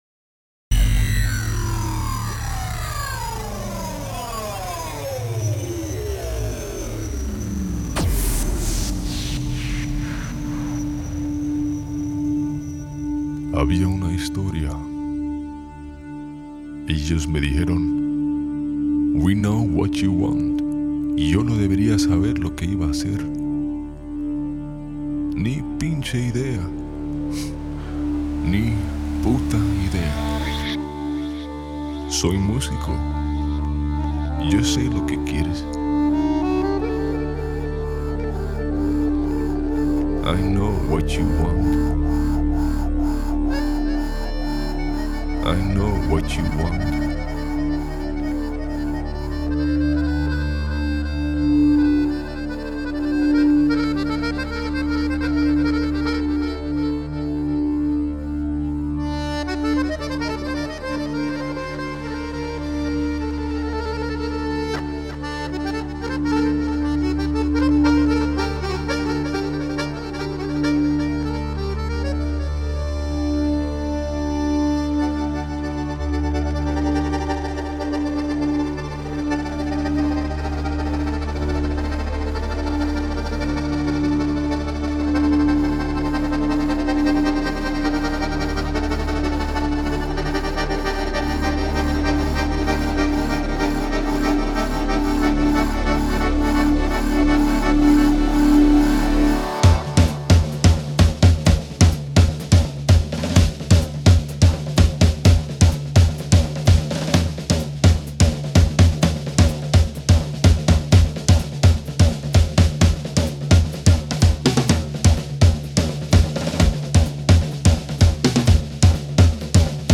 Genre: Electronic, Gypsy Jazz, Balkan, Folk, World